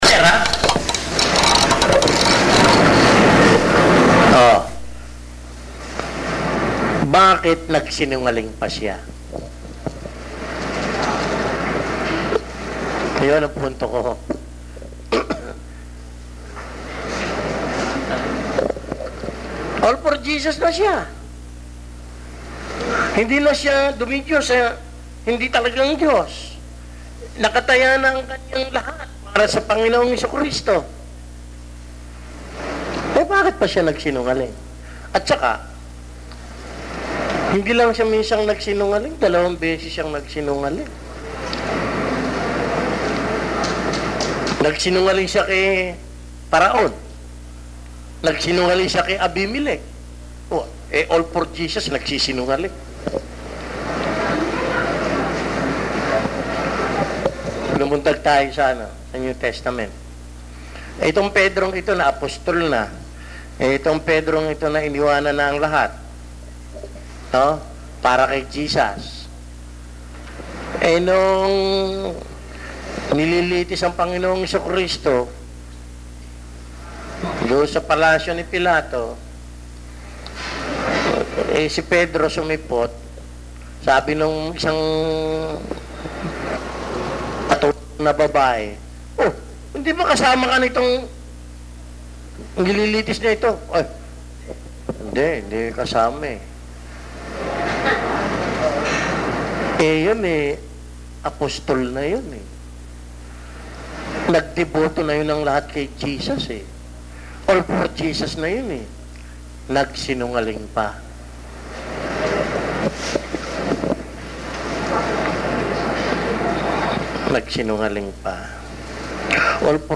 Posted on March 14, 2015 in audio, audio bible study, Bible study, faith, God, JESUS CHRIST, lectures, sermons, spirituality